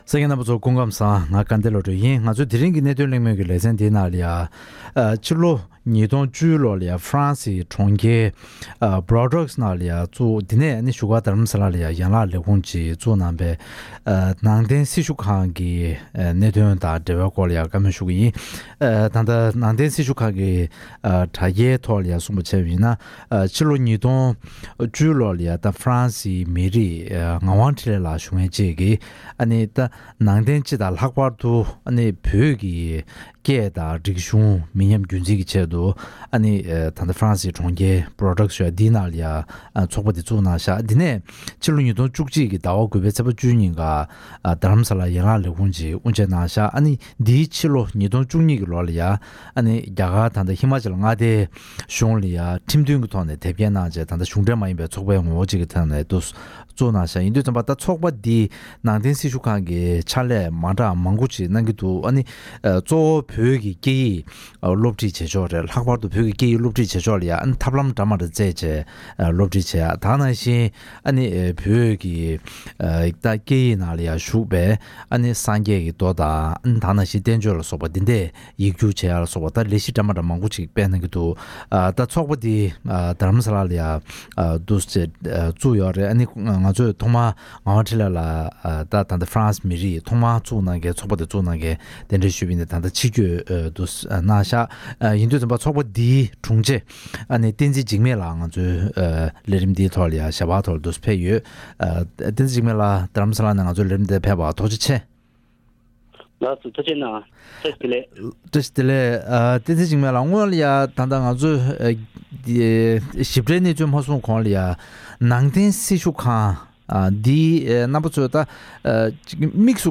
༄༅། །ཐེངས་འདིའི་གནད་དོན་གླེང་མོལ་གྱི་ལེ་ཚན་ནང་། ནང་བསྟན་སྤྱི་དང་བྲེ་བྲག་ཏུ་བོད་ཀྱི་སྐད་ཡིག་དང་རིག་གཞུང་མི་ཉམས་རྒྱུན་འཛིན་གྱི་ཆེད་དུ་ཕྱི་ལོ་༢༠༡༠ལོར་གསར་འཛུགས་གནང་བའི་ནང་བསྟན་སྲི་ཞུ་ཁང་ཞེས་པ་དེའི་སྐོར་གླེང་མོལ་ཞུས་པ་ཞིག་གསན་རོགས་གནང་།